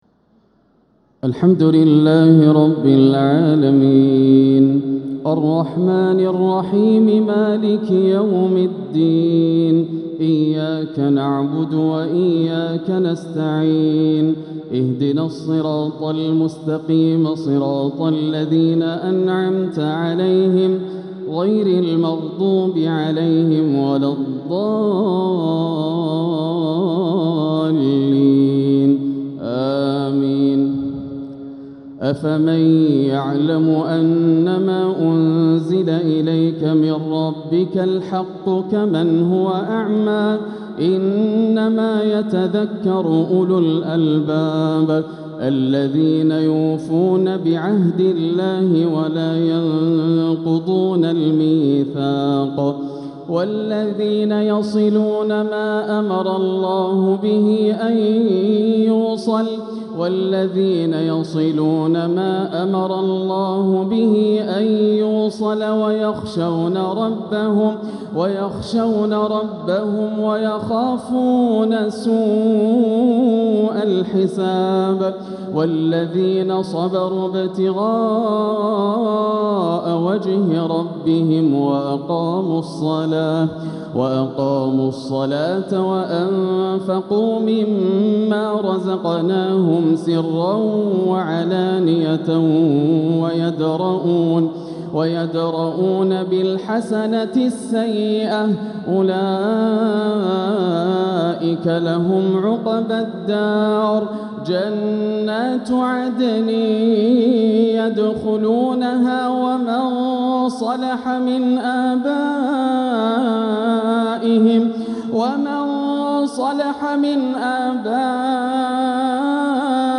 تراويح ليلة 17 رمضان 1446هـ من سورتي الرعد (19-43) و إبراهيم (1-18) > الليالي الكاملة > رمضان 1446 هـ > التراويح - تلاوات ياسر الدوسري